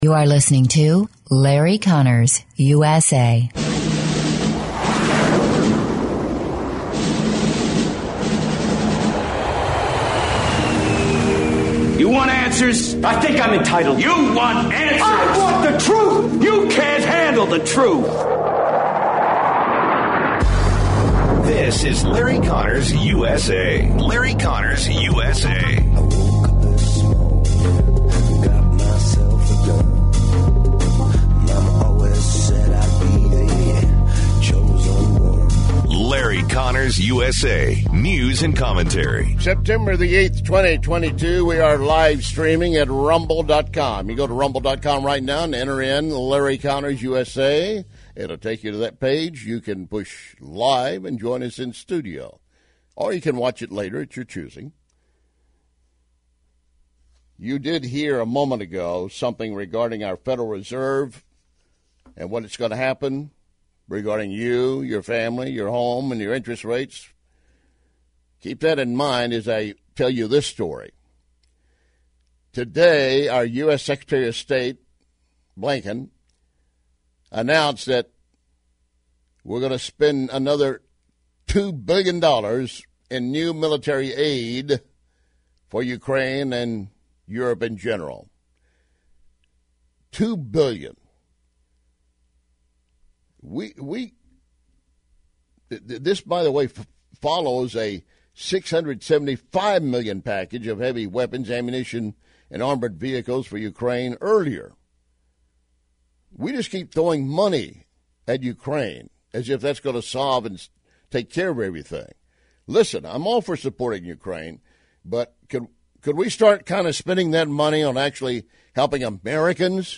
News and Commentary. Dems making up their own storyline with Trump raid.